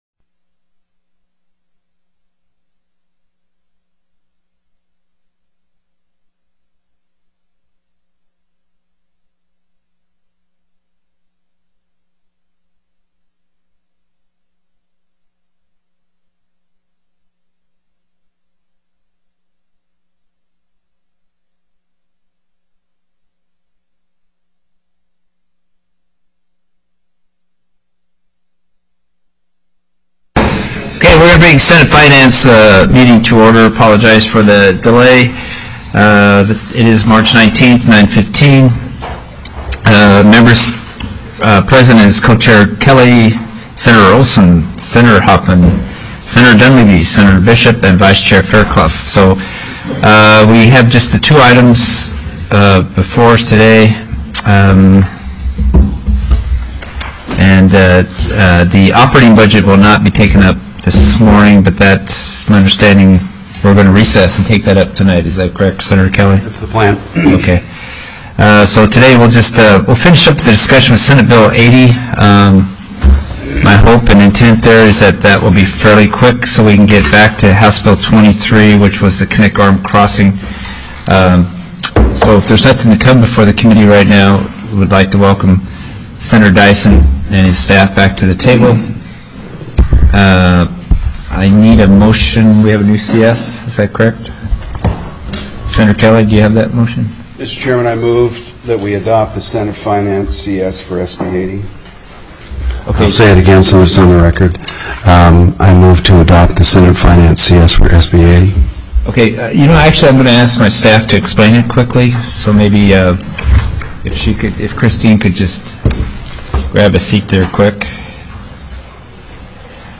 03/19/2014 09:00 AM Senate FINANCE
HB 266 APPROP: OPERATING BUDGET/LOANS/FUNDS TELECONFERENCED Heard & Held